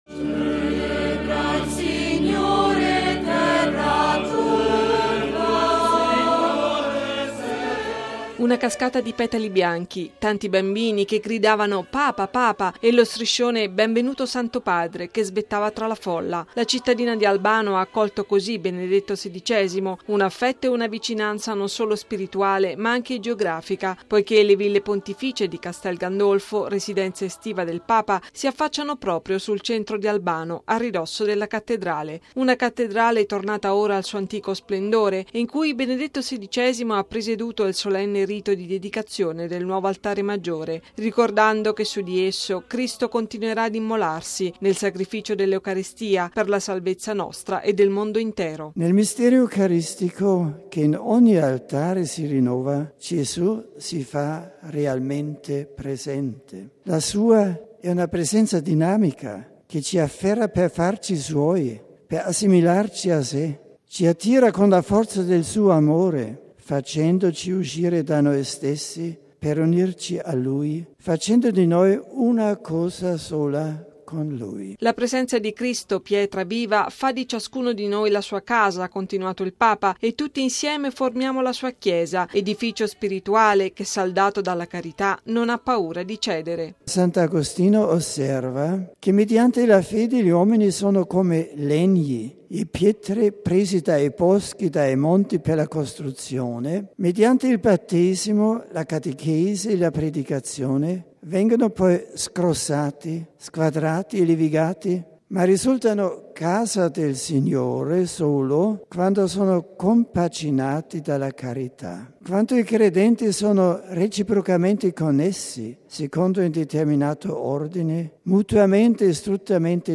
(canto)